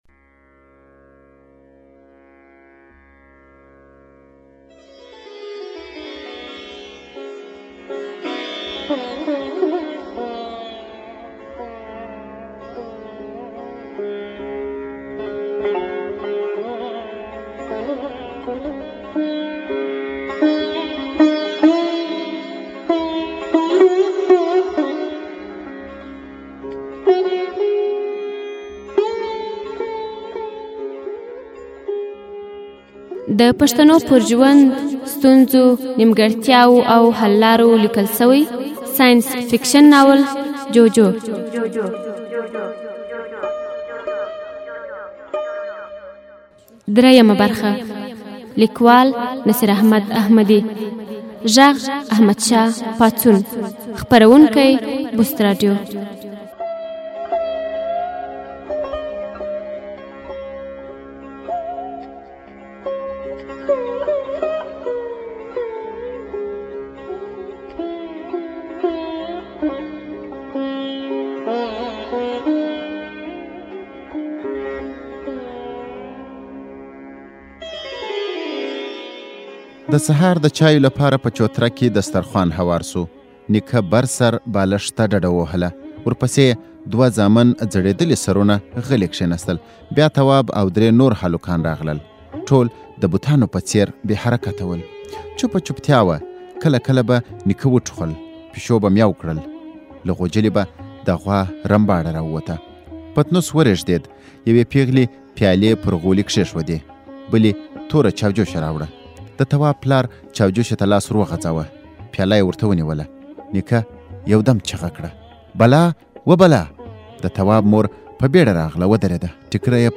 ږغیز ناولونه